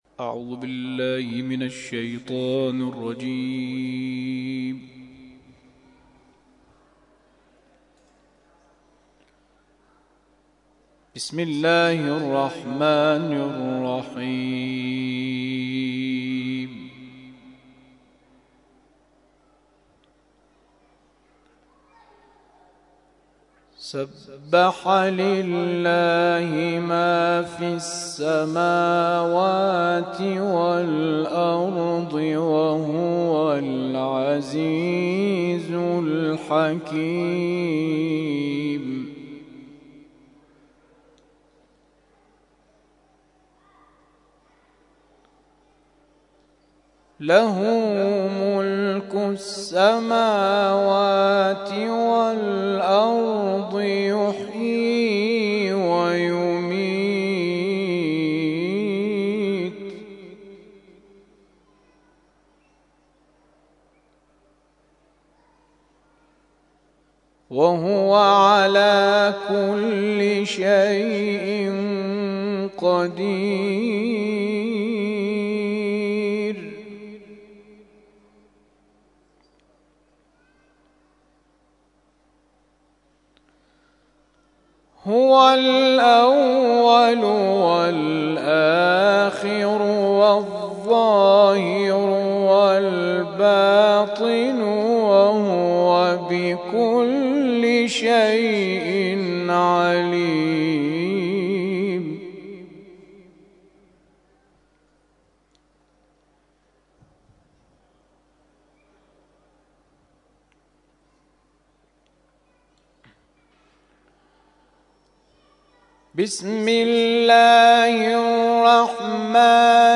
تلاوت ظهر - سوره واقعه آیات ( ۷۵ الی ۹۱) Download